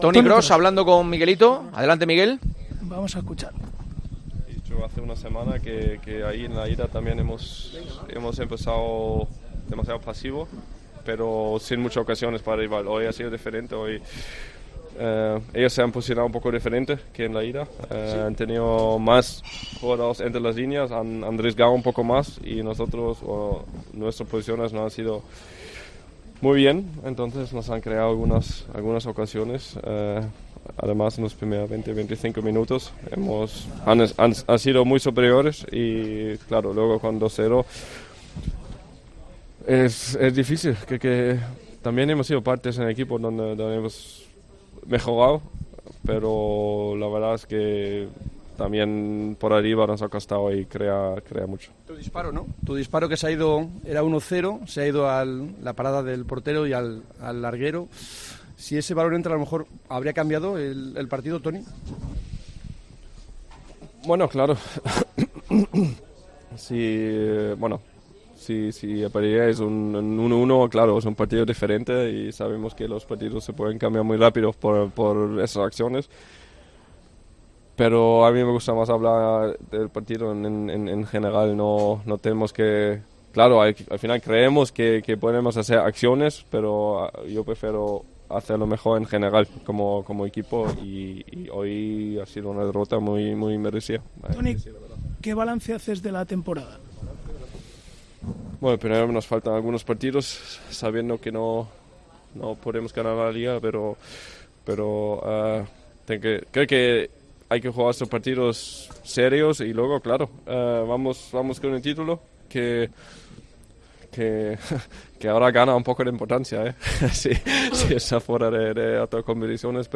El centrocampista alemán del Real Madrid valoró en zona mixta la eliminación del equipo blanco.